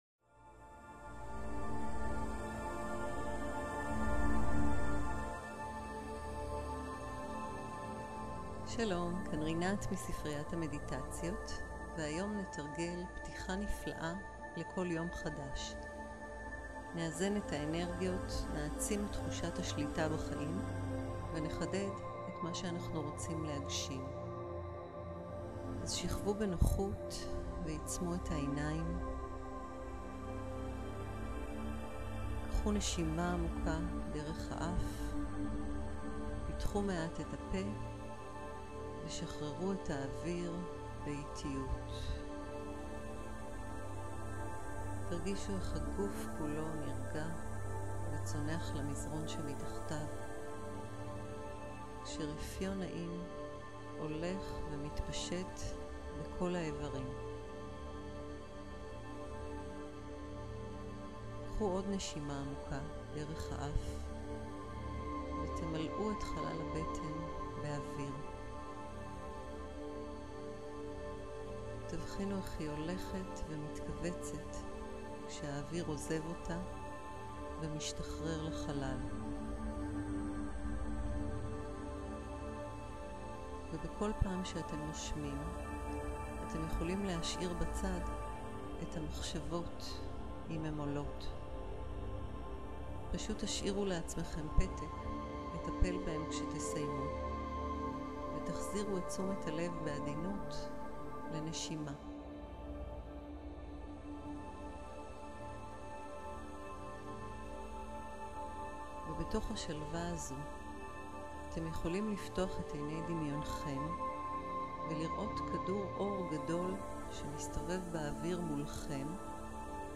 הקלטה מצגות מצגת 1 מצגת 2 שמע מדיטציה לבוקר תרגילים תרגיל 1 להלהלהללהלהללהל תרגיל 2 גדכגדכגדכדג כ קישורים לורם איפסום דולור סיט אמט, קונסקטורר אדיפיסינג אלית לפרומי בלוף קינץ תתיח לרעח.